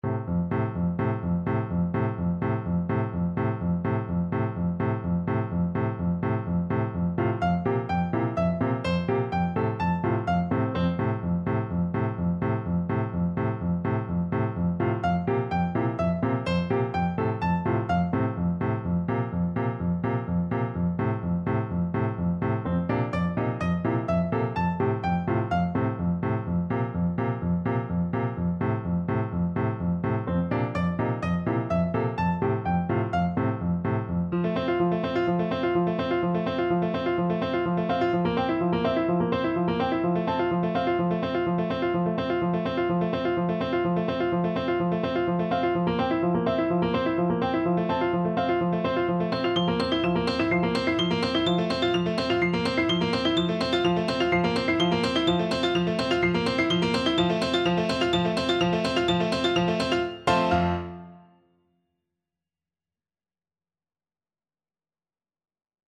F major (Sounding Pitch) (View more F major Music for Oboe )
Allegro moderato (=126) (View more music marked Allegro)
Classical (View more Classical Oboe Music)